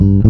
Basstha2.wav